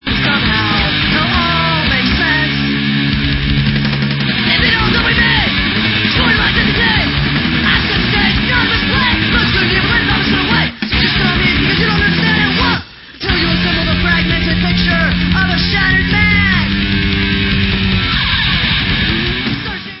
sledovat novinky v oddělení Rock/Hardcore